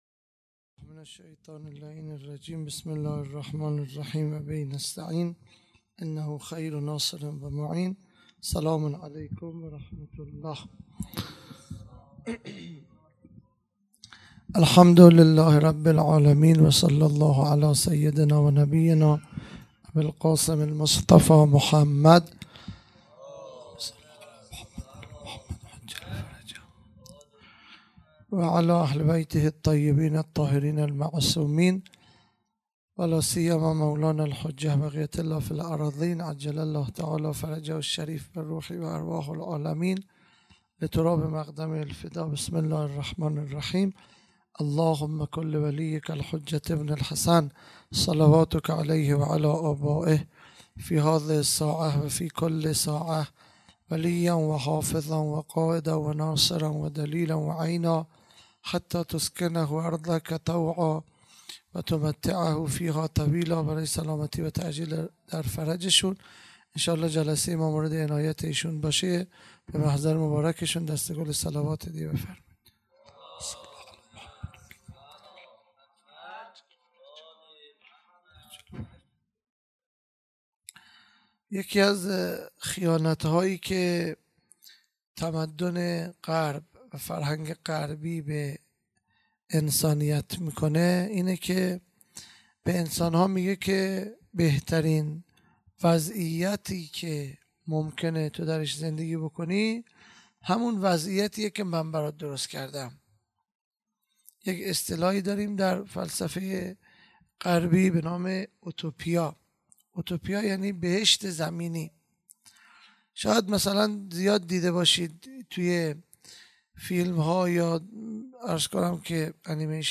خیمه گاه - هیئت بچه های فاطمه (س) - سخنرانی
جلسه هفتگی هیات به مناسبت شهادت حضرت حمزه(ع)